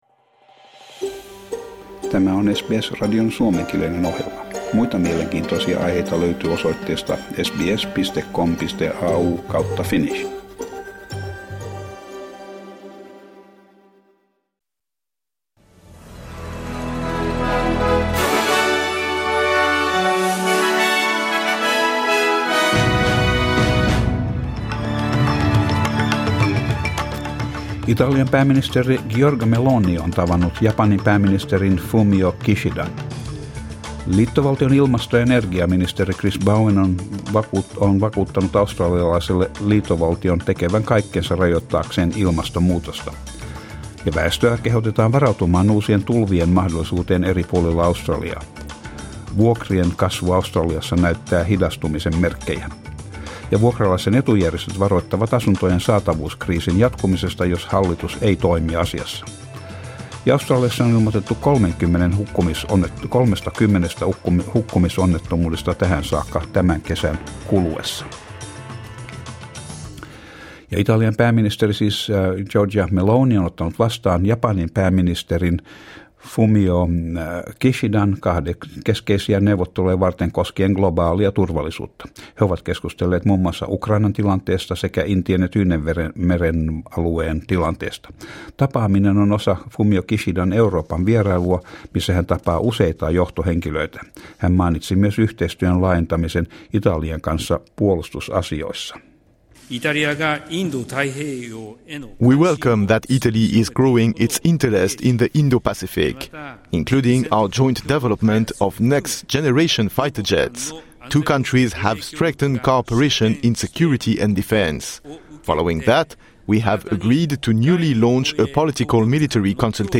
Uutiset 11.1.23